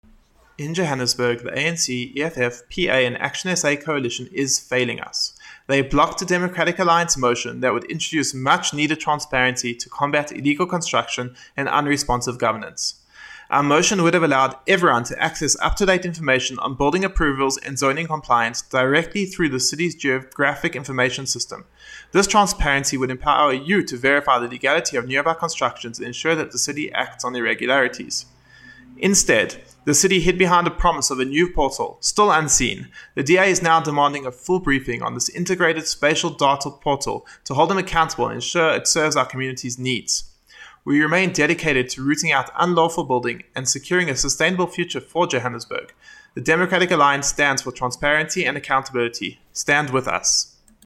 Note to Editors: Please find an English soundbite by Cllr Daniel Schay